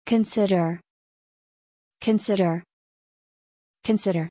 発音記号：/kənˈsɪdər/